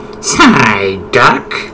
Cries
PSYDUCK.mp3